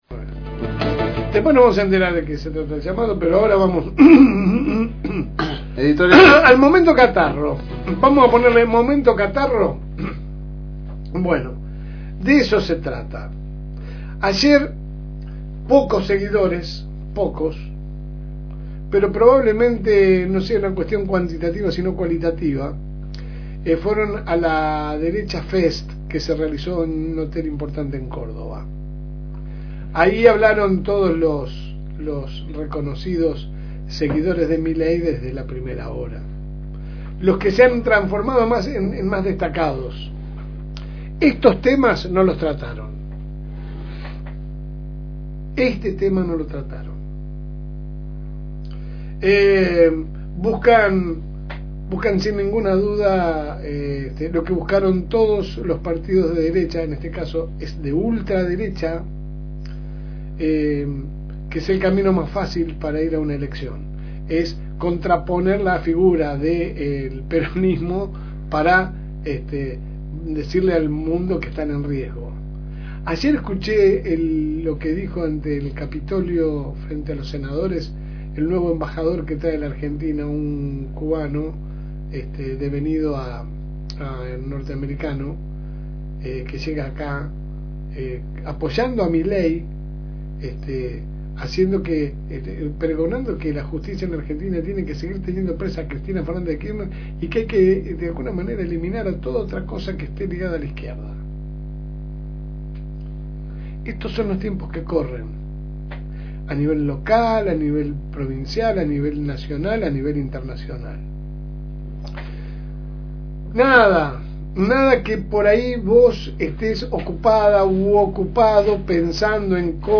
Que sale de lunes a viernes por el aire de la Fm Reencuentro 102.9 de 10 a 12 HS